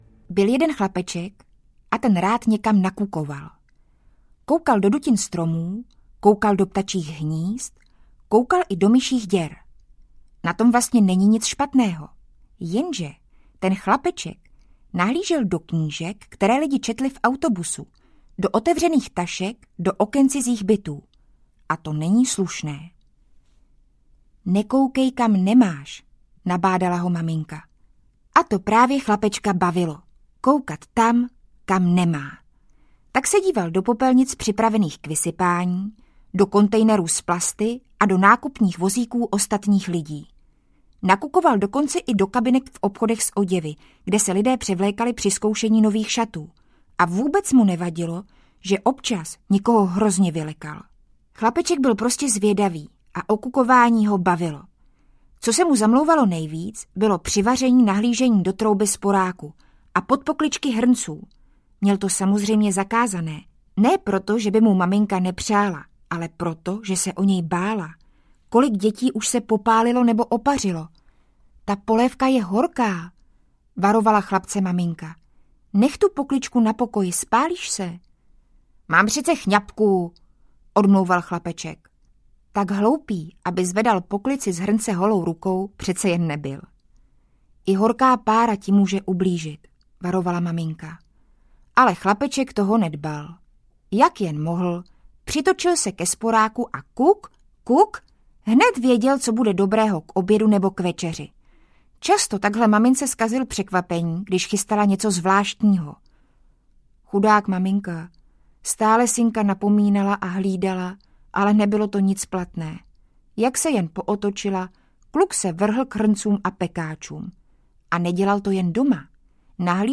Příšerní nezbedníci audiokniha
Ukázka z knihy
• InterpretDavid Prachař, Linda Rybová